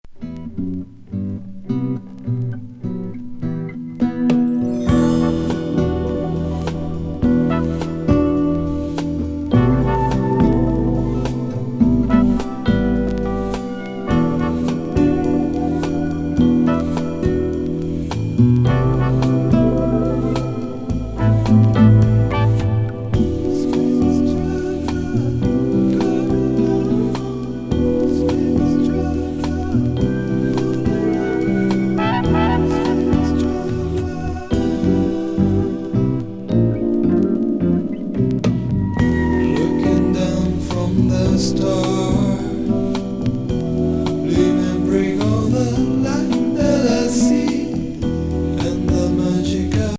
シャンソン歌手
グルーヴィ歌謡カバー